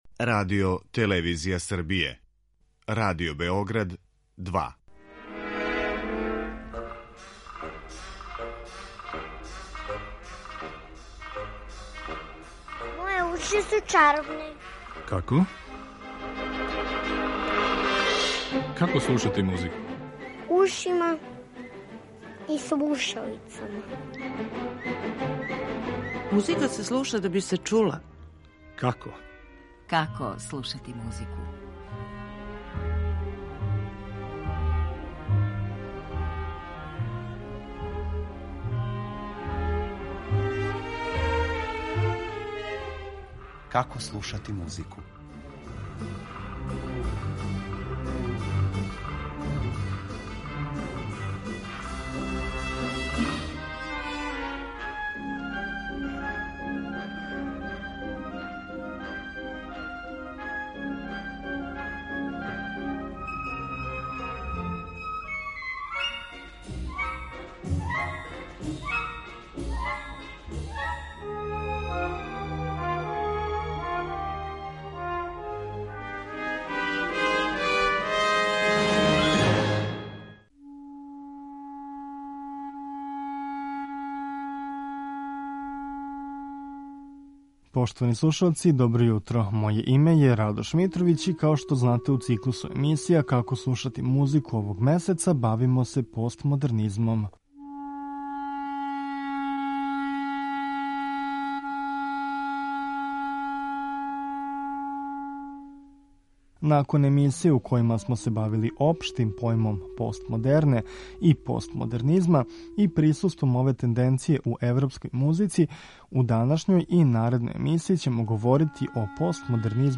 Гости емисије су музиколози